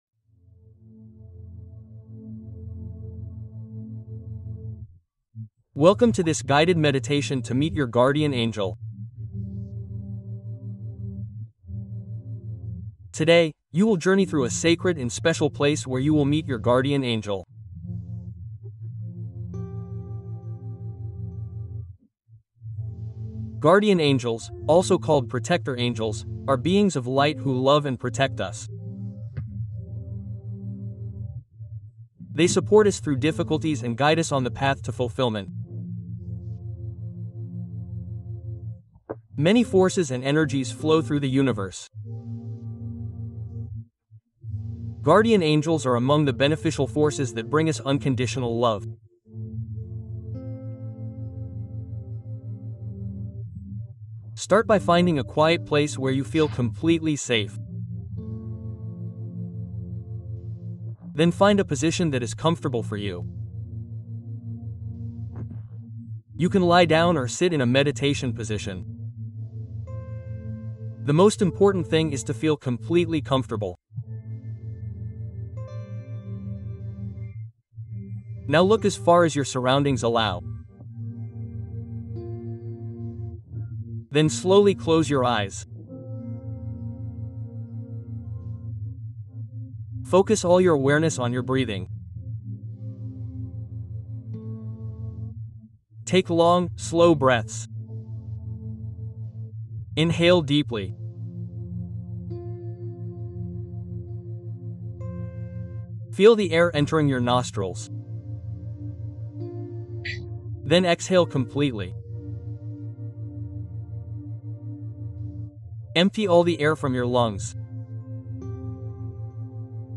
Rencontre ton Ange Gardien avec cette méditation guidée